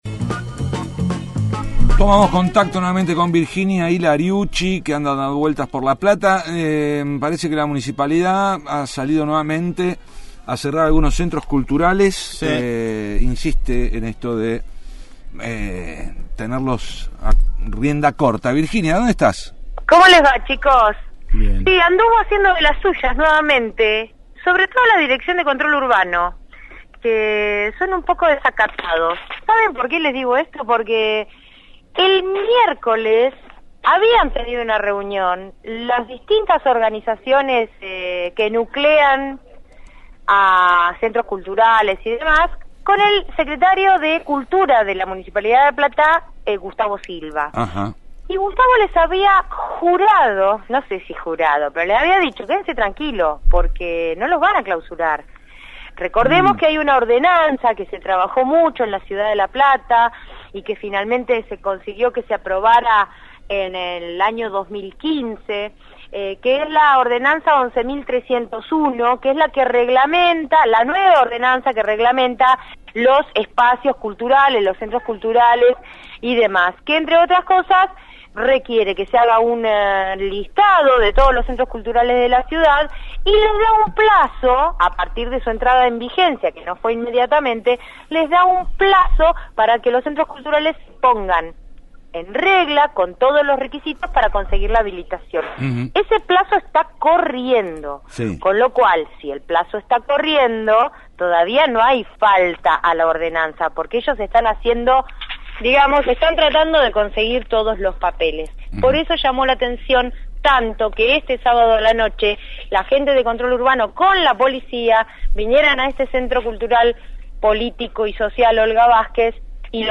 Las noticias de la calle